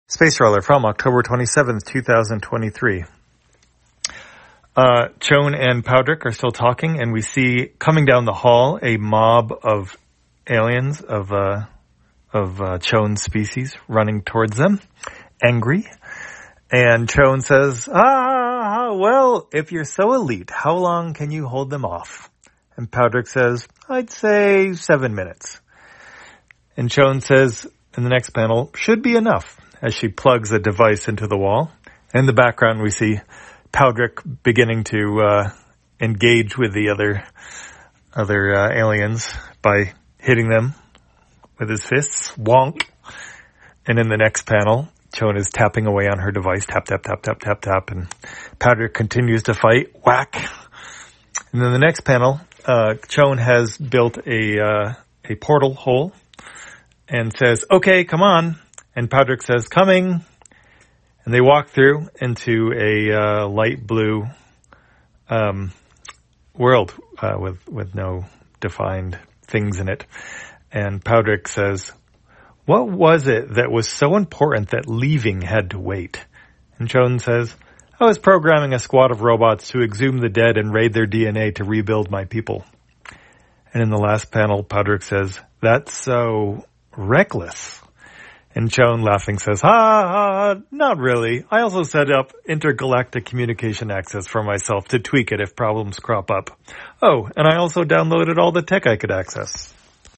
Spacetrawler, audio version For the blind or visually impaired, October 27, 2023.